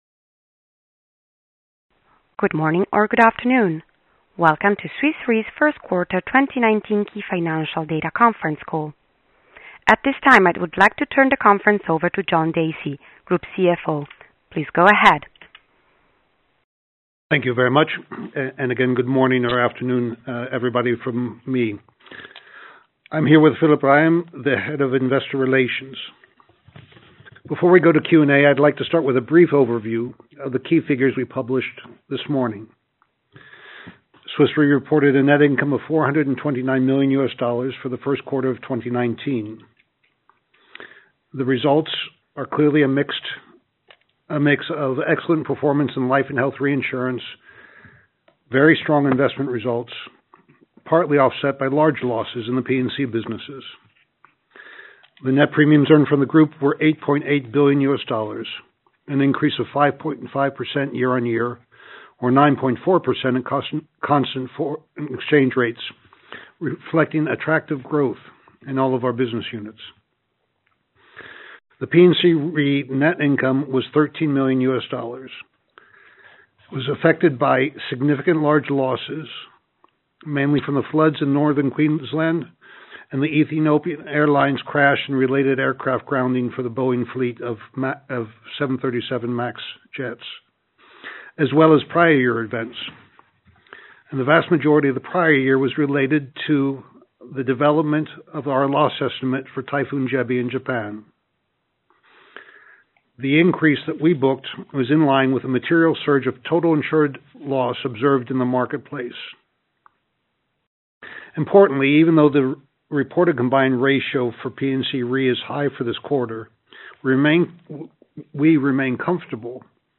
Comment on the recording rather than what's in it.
q1-2019-call-recording.mp3